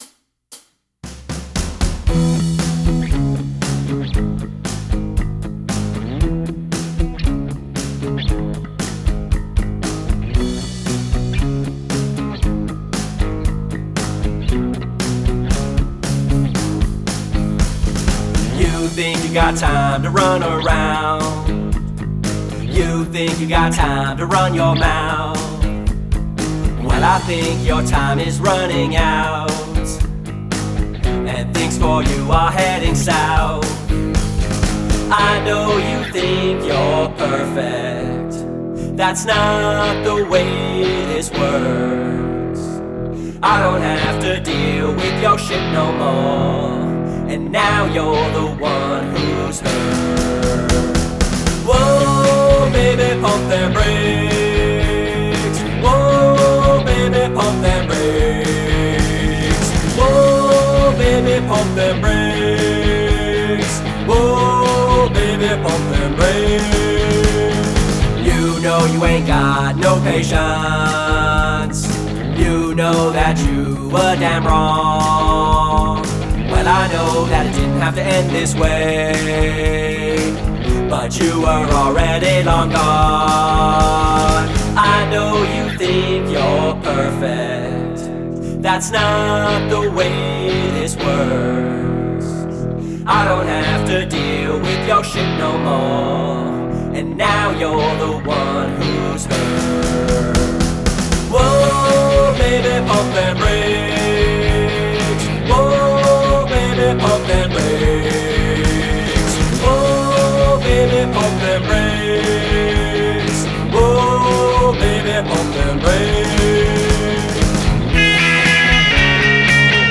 Diss Track
The singing & the music was pretty good.